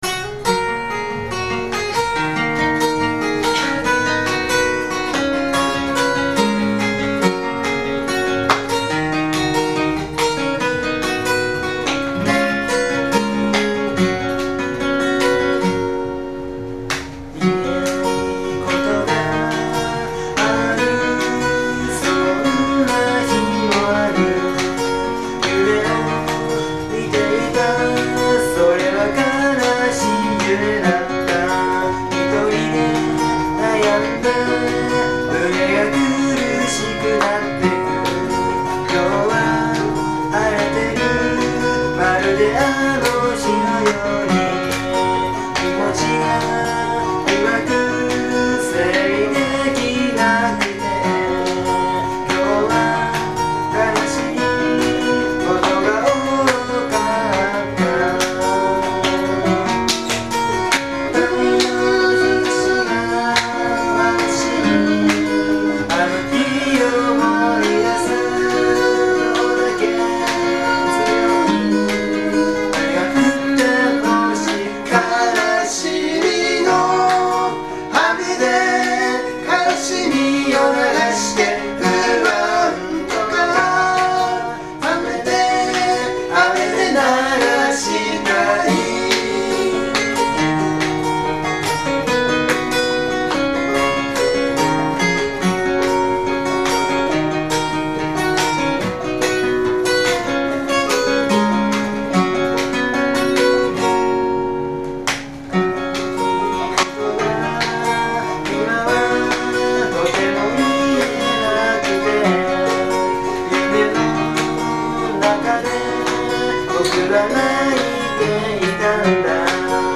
Bluegrass style Folk group
企画名: Studio Live III
録音場所: 与野本町BIG ECHO
リードボーカル、ギター
コーラス、バンジョー
コーラス、パーカッション